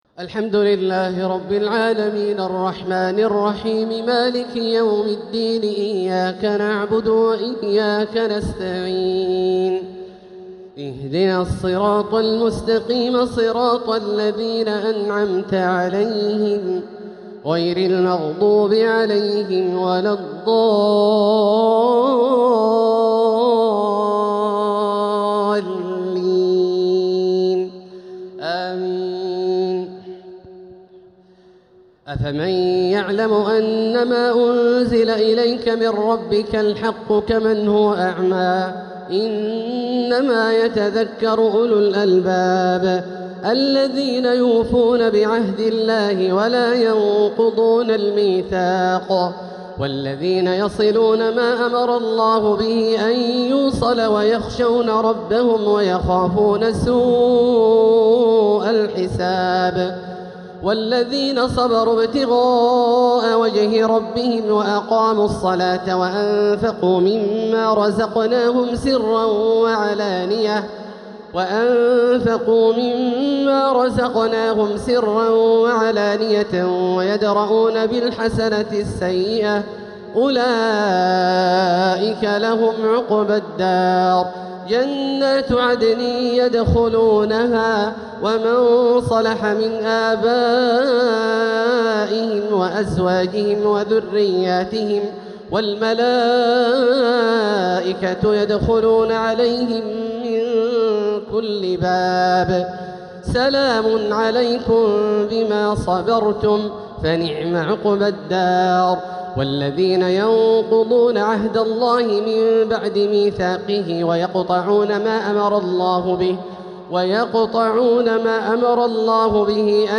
بصوته العذب الشيخ عبدالله الجهني يمتع الأسماع من سورتي الرعد (19-43) إبراهيم (1-18) | تراويح ليلة 17 رمضان 1447هـ > تراويح 1447هـ > التراويح - تلاوات عبدالله الجهني